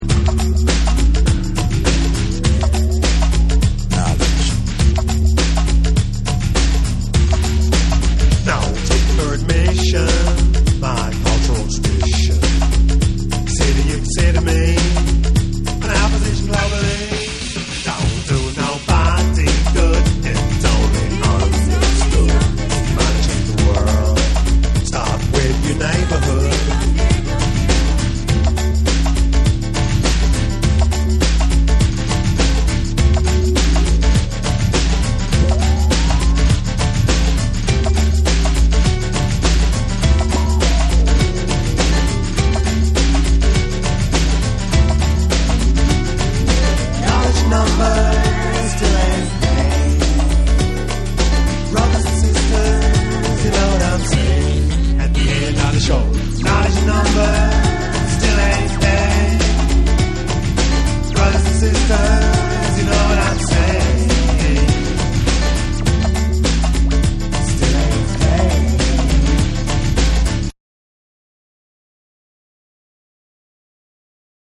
BREAKBEATS / CLUB